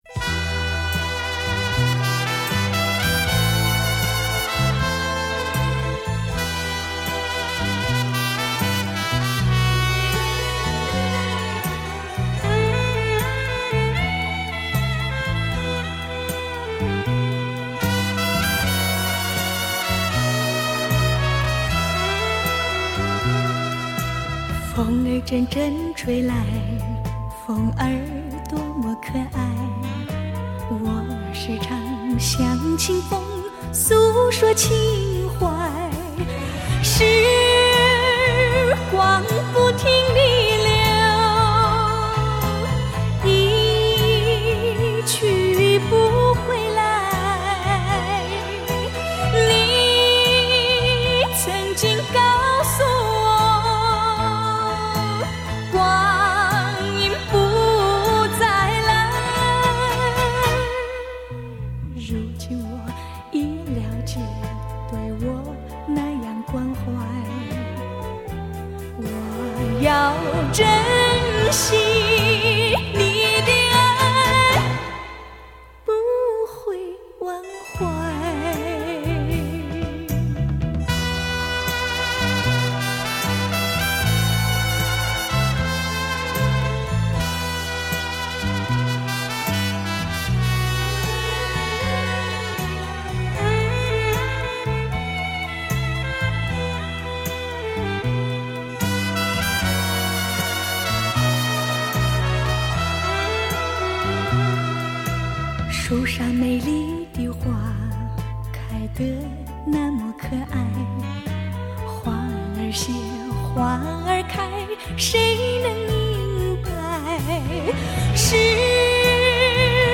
现场灌录，本专辑为当年岛国系列的第一张专辑。